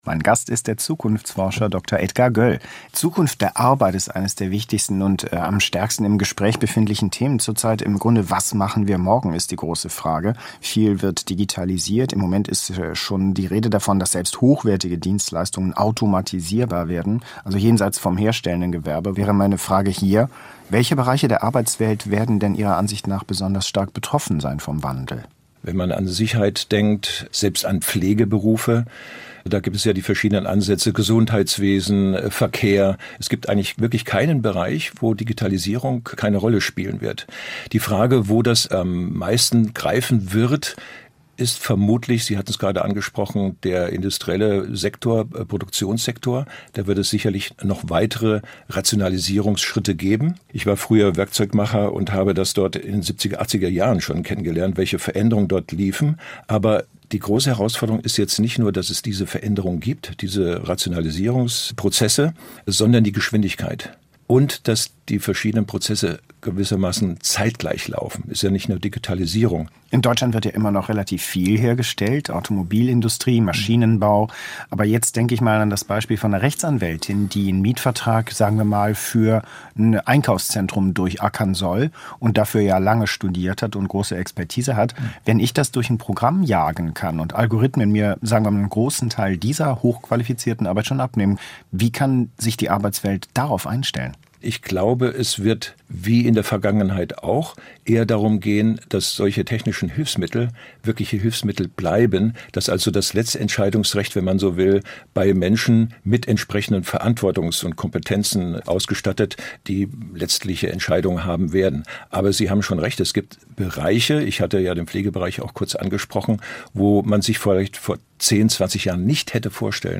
SWR2 Tagesgespräch und Interview der Woche